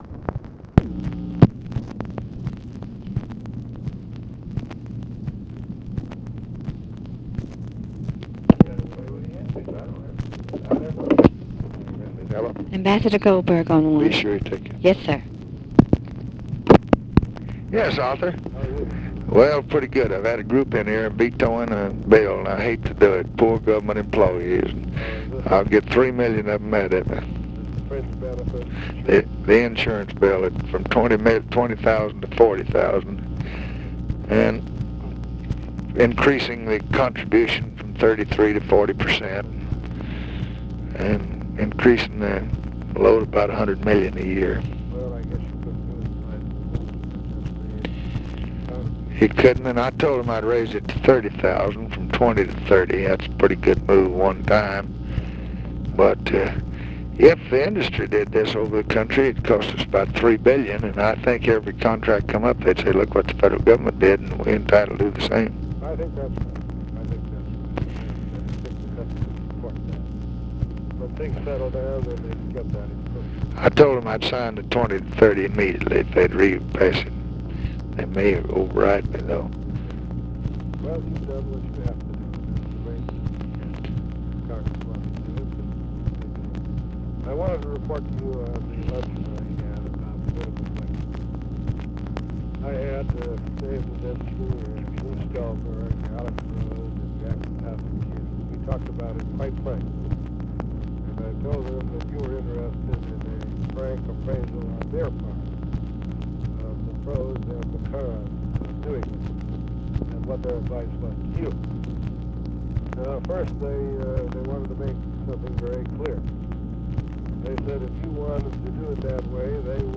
Conversation with ARTHUR GOLDBERG and OFFICE SECRETARY, September 13, 1966
Secret White House Tapes